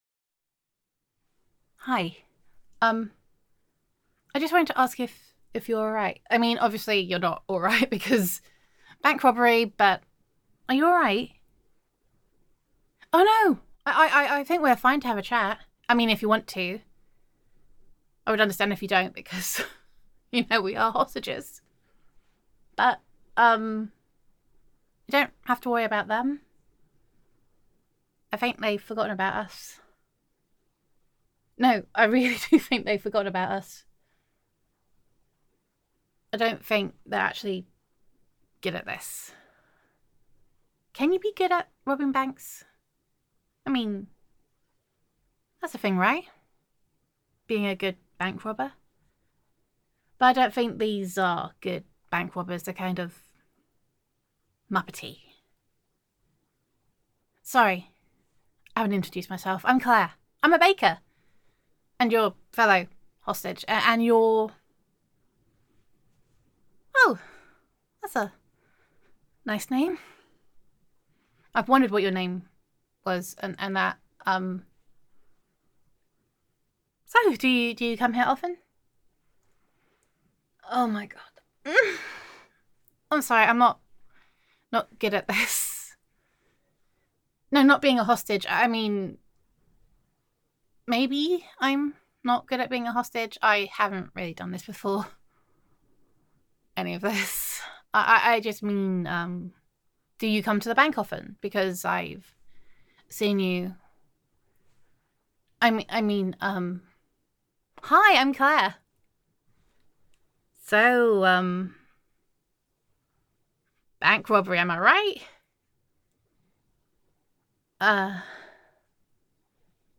[F4A] How You Met Your New Girlfriend
[Awkward Pauses Because Awkward]